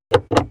Encender la luz interior de un coche RAV4